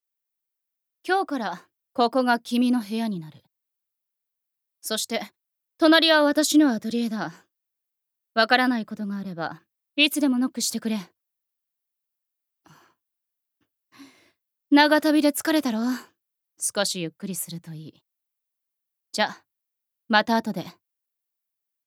Voice Sample
セリフ２